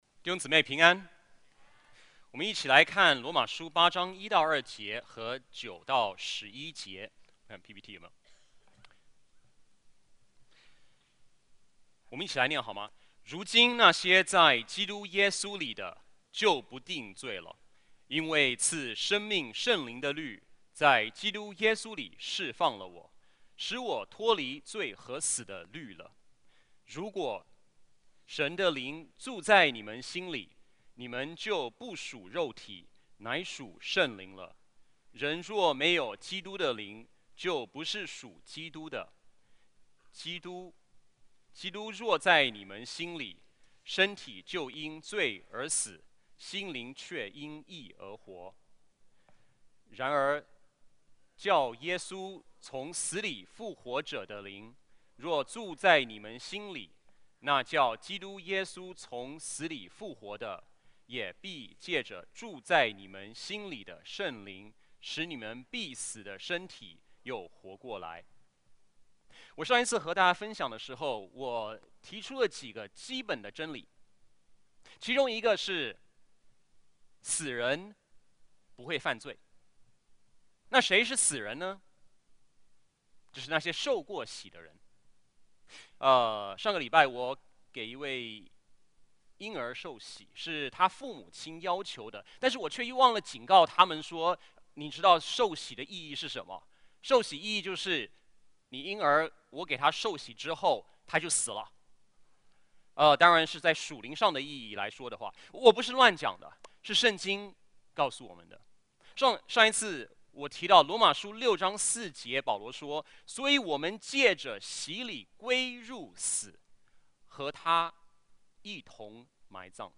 主日证道 |  在基督里的新生命